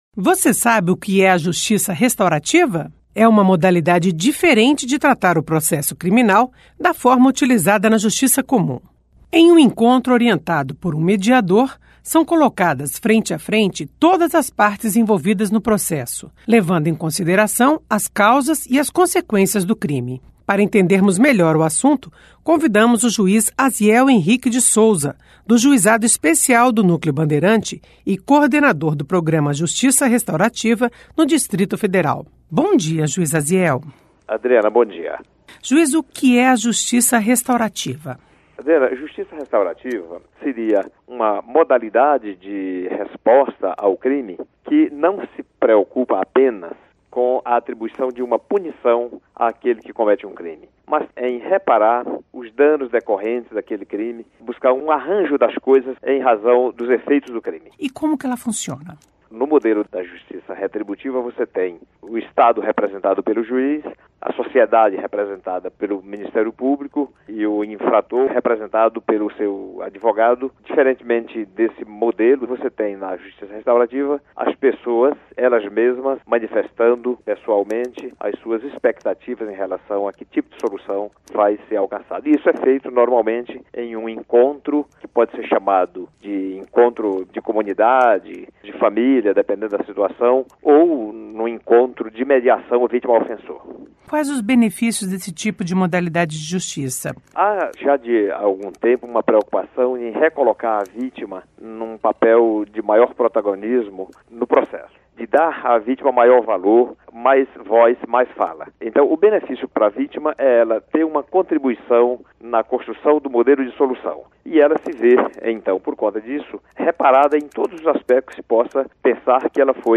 Entrevista com o juiz Asiel Henrique de Sousa, coordenador do Programa Justiça Restaurativa no Distrito Federal.